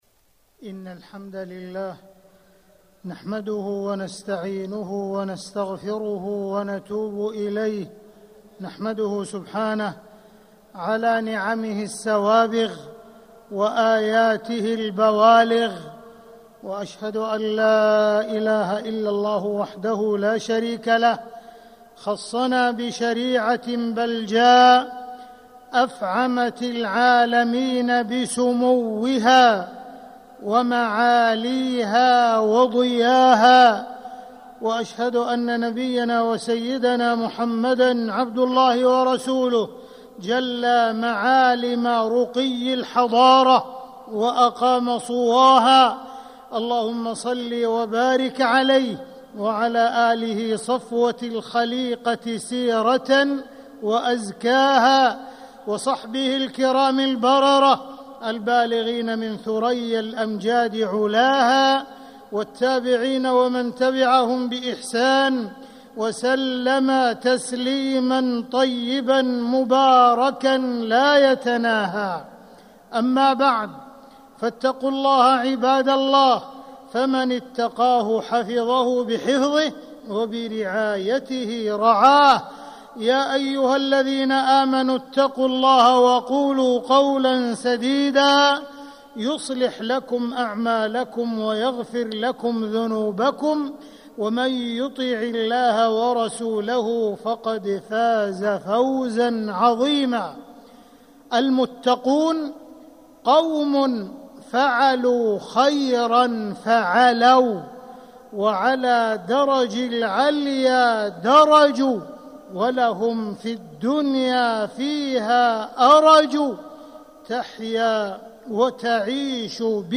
مكة: الوقوف عند منهج السلف الأوائل - عبد الرحمن بن عبدالعزيز السديس (صوت - جودة عالية